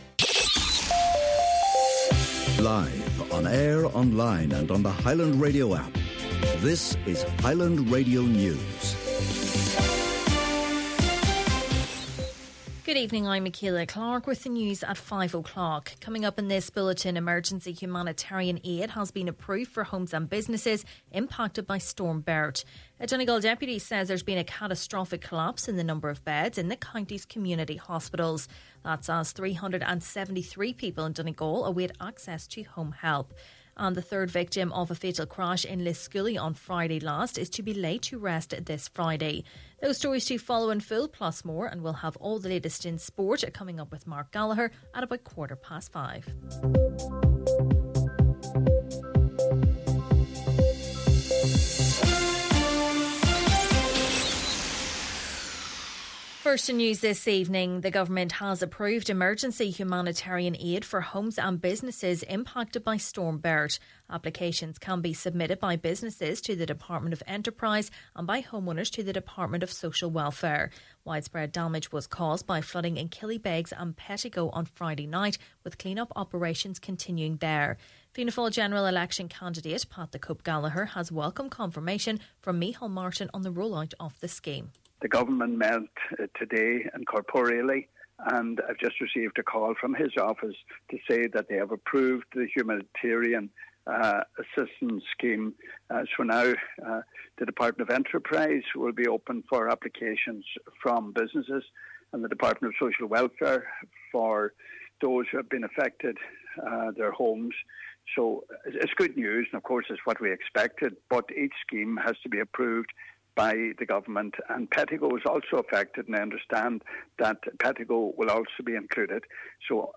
Main Evening News, Sport and Obituaries – Wednesday, November 27th